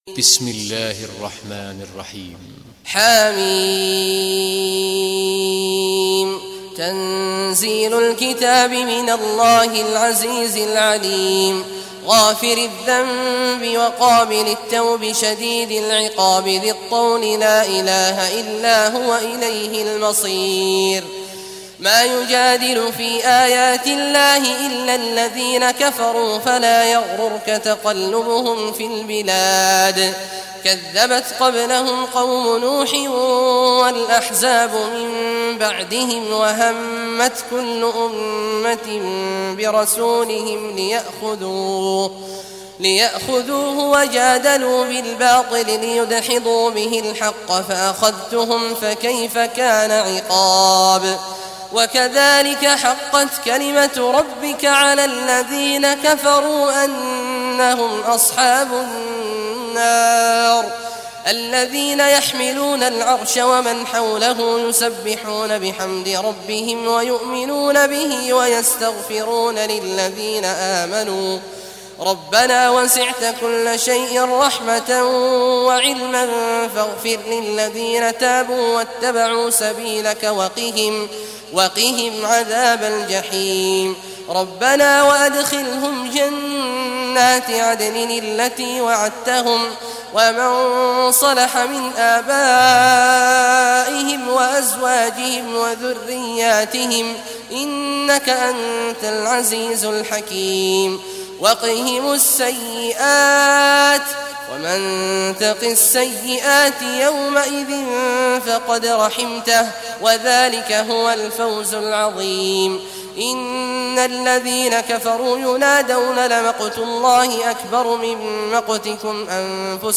Surah Ghafir Recitation by Sheikh Awad al Juhany
Surah Ghafir, listen or play online mp3 tilawat / recitation in Arabic in the beautiful voice of Sheikh Abdullah Awad al Juhany.